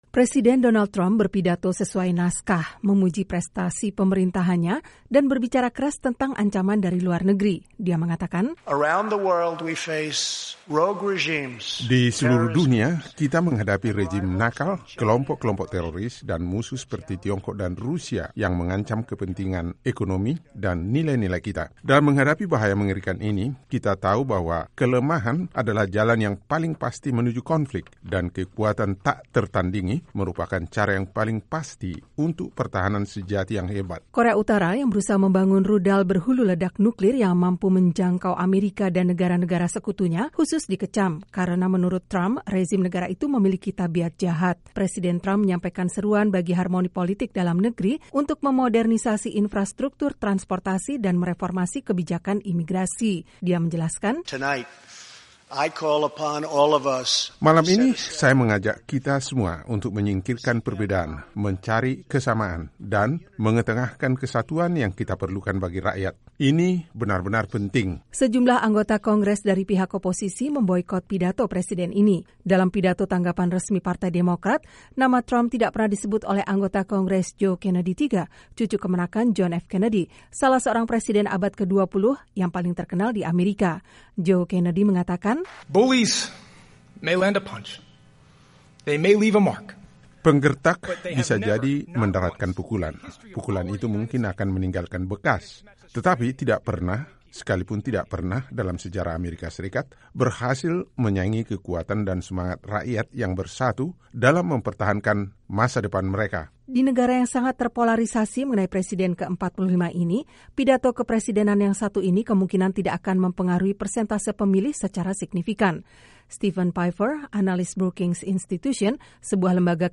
Presiden Amerika Serikat Donald Trump menyampaikan Pidato Kenegaraan (State of The Union) di hadapan para anggota Kongres Amerika di Gedung Capitoll, Washington DC, Selasa, 30 Januari 2018.
Presiden Donald Trump berpidato sesuai naskah, memuji prestasi pemerintahannya dan berbicara keras tentang ancaman dari luar negeri.